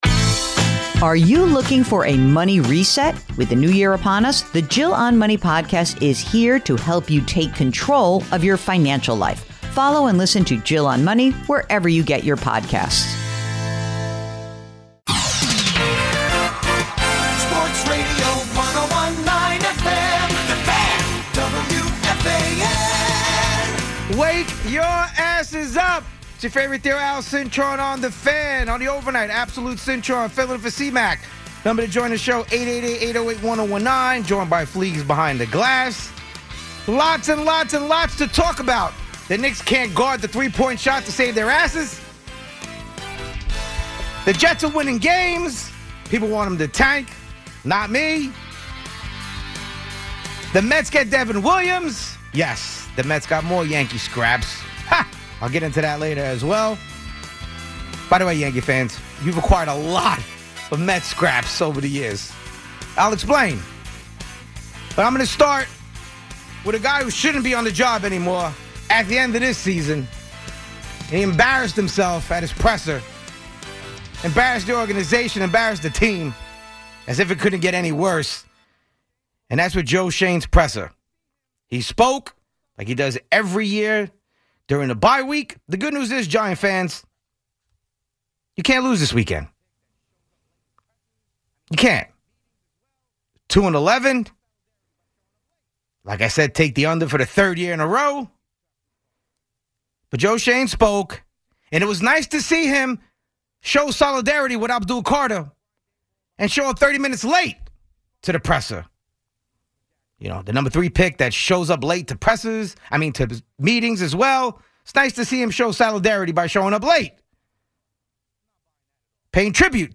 WFAN On-Demand is a curated snapshot of New York sports talk at its best, delivering the moments fans can’t afford to miss. Featuring standout interviews, sharp commentary, and memorable segments from across the WFAN lineup, the podcast spans everything from Yankees and Mets headlines to Giants and Jets debates, plus Knicks, Nets and the biggest national sports stories.